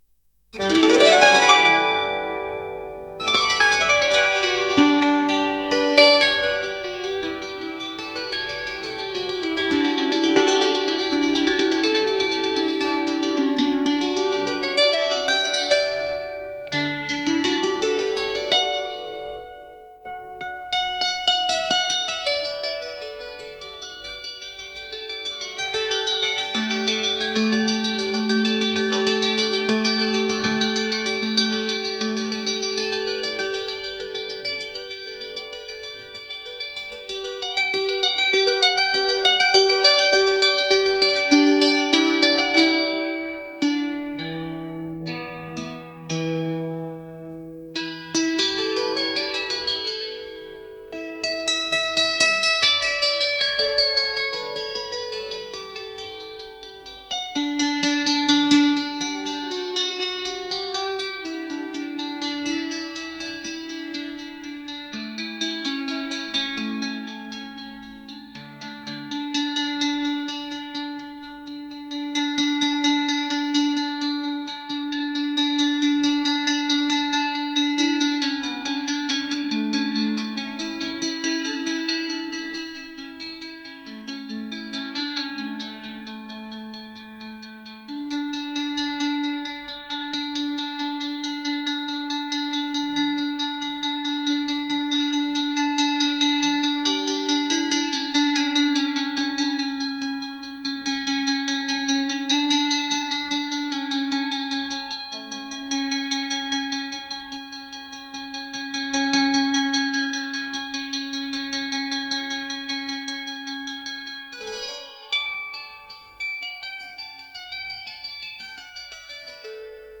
traditional | classical